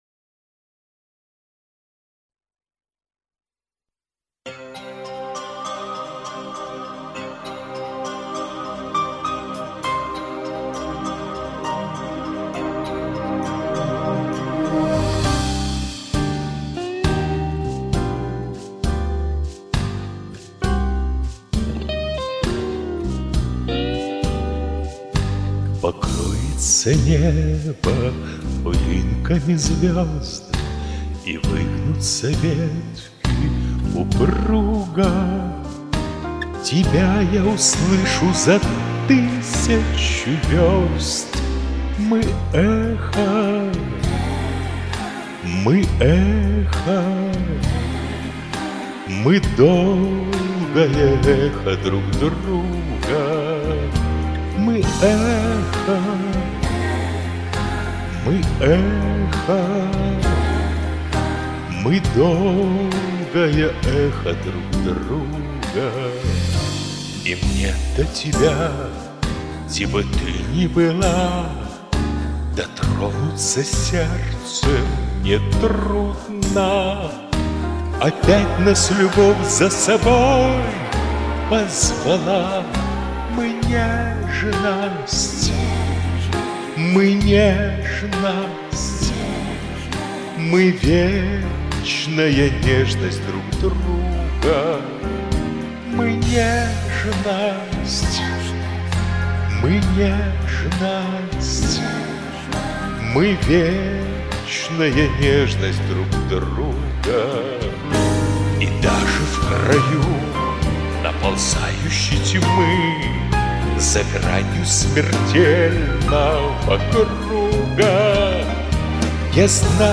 эта песня прозвучала практически как военный марш
что-то от блюза, что-то от свинга